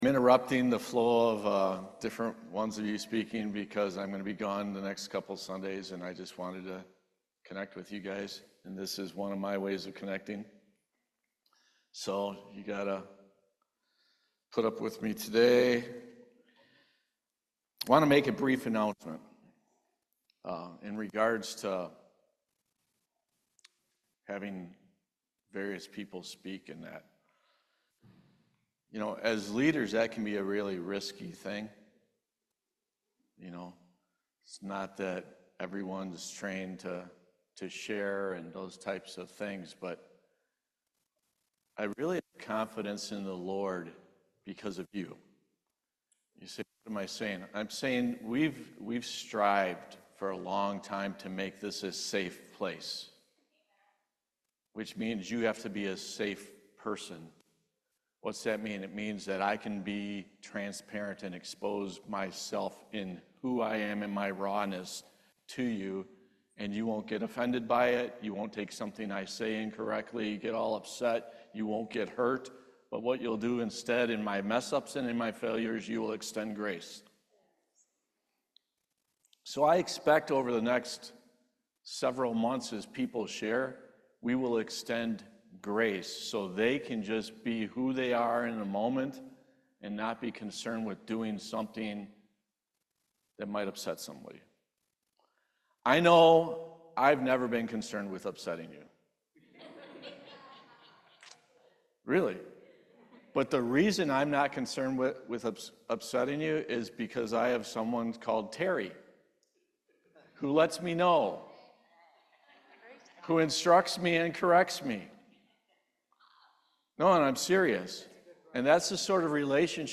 Series: Testimony
Service Type: Main Service